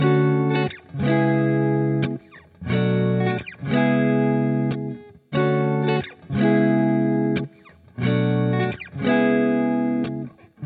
描述：嘻哈吉他
标签： 90 bpm Hip Hop Loops Guitar Electric Loops 1.80 MB wav Key : D
声道立体声